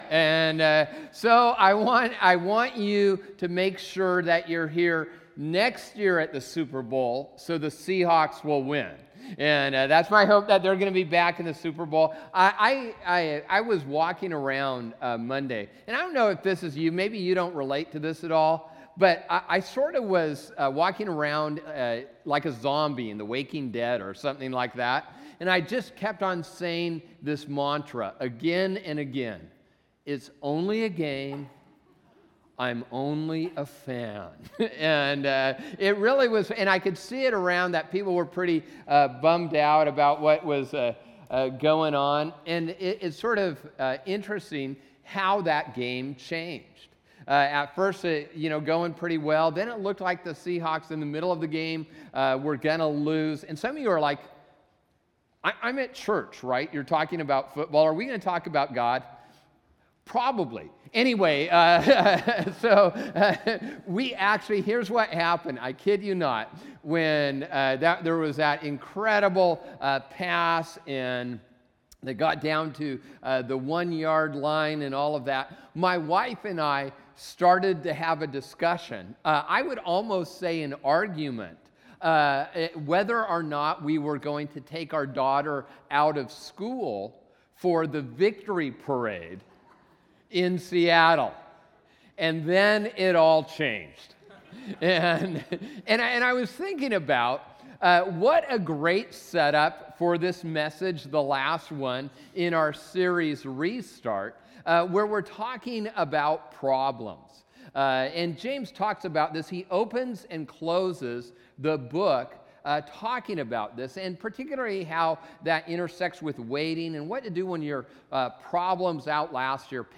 Date: 02/08/2015 Message Begins at 20:13